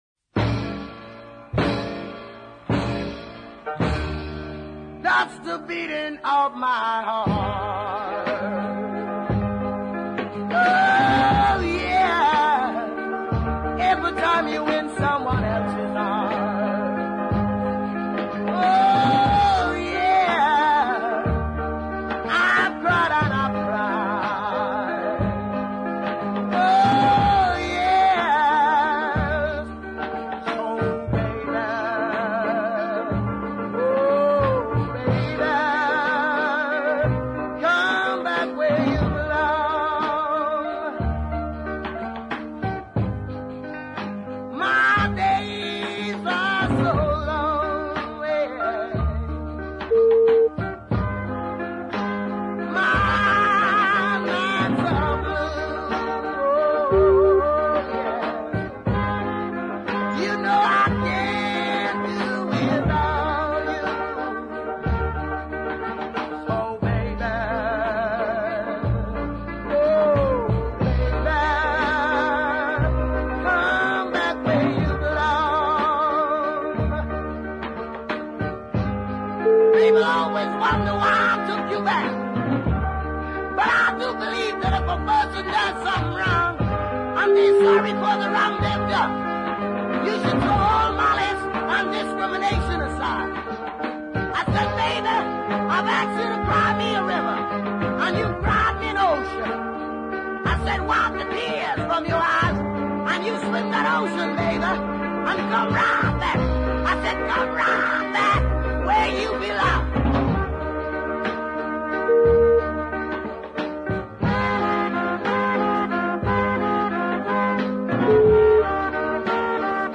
The gritty deep